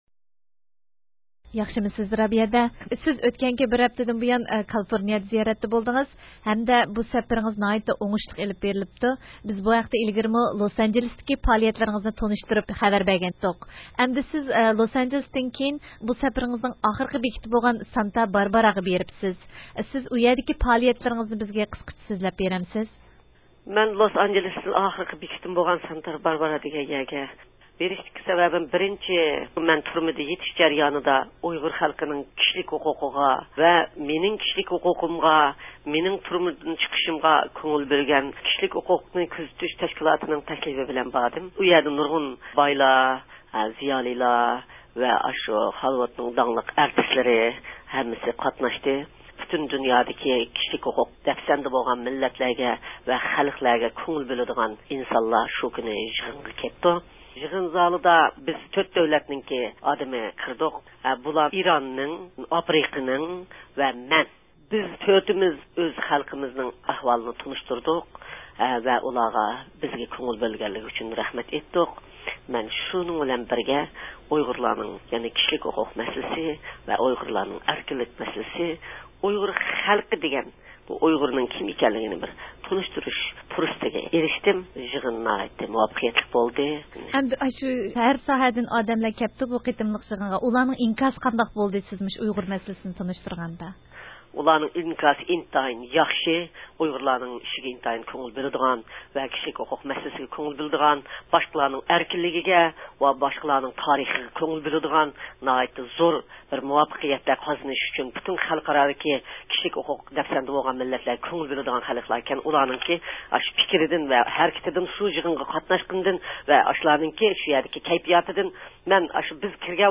سۆھبىتىدىن ئاڭلاڭ.